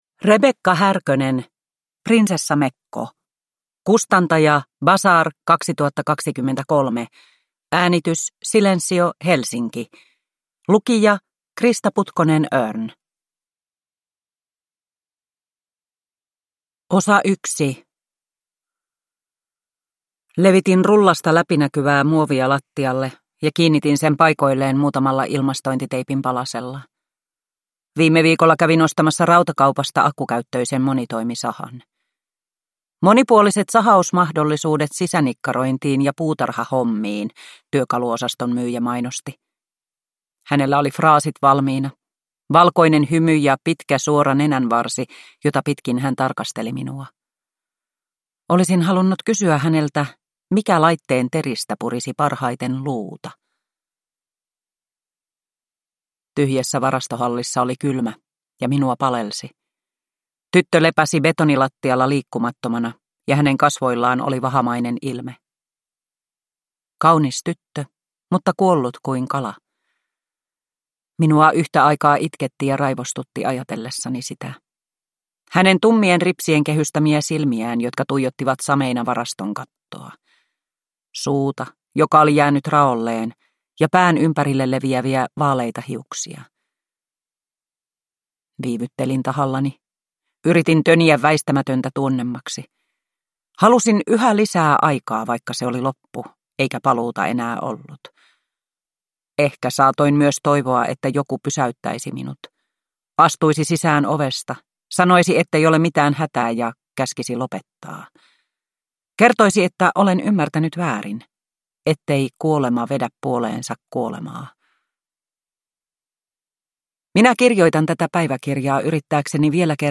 Prinsessamekko – Ljudbok – Laddas ner